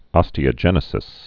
(ŏstē-ə-jĕnĭ-sĭs)